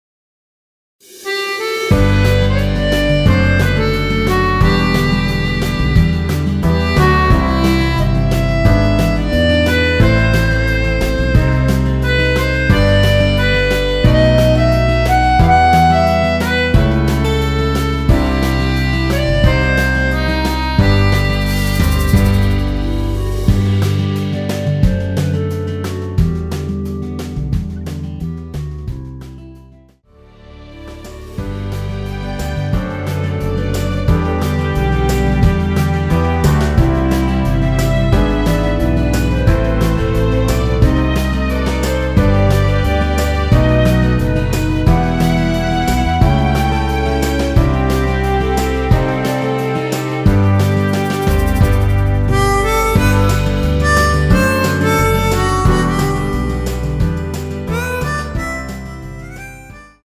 원키에서(-3)내린 멜로디 포함된 MR입니다.(미리듣기 확인)
멜로디 MR이라고 합니다.
앞부분30초, 뒷부분30초씩 편집해서 올려 드리고 있습니다.